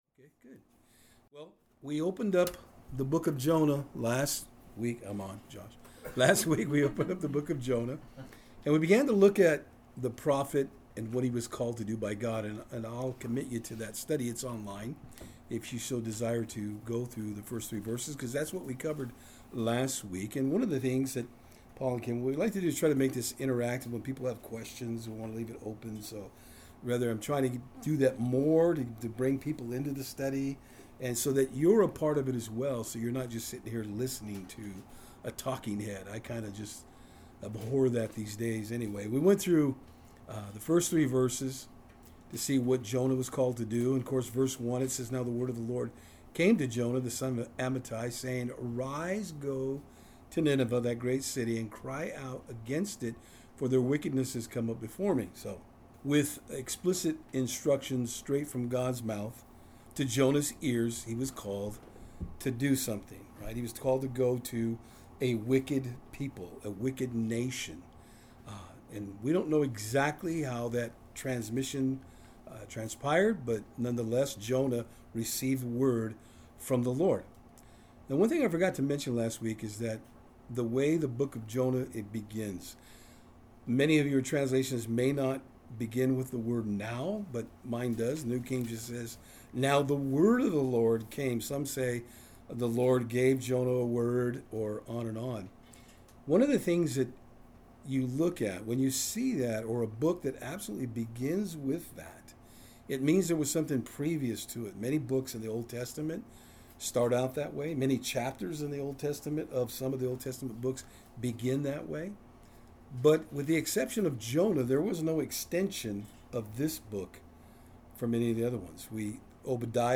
Jonah 1:4-16 Service Type: Saturdays on Fort Hill In our study through the book of Jonah we see how he did not want to carry out Gods message to the Ninevites.